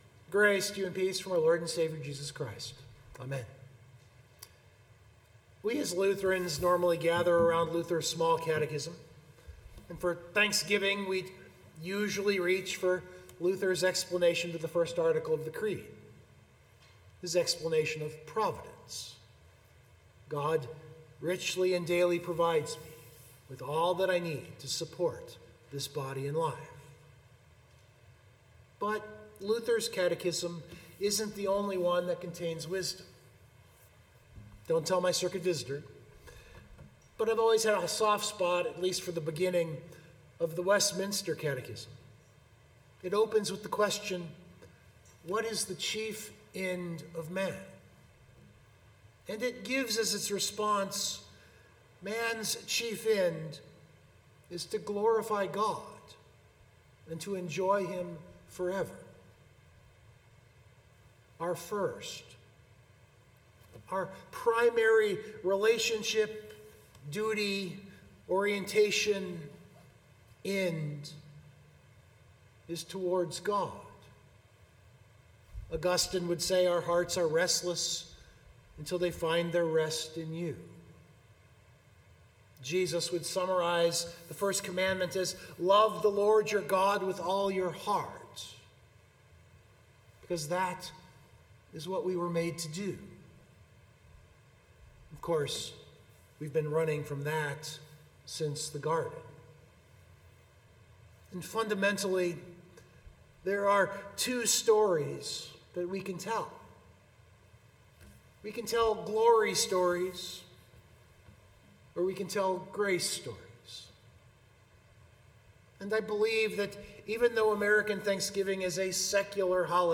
This sermon isn’t terrible.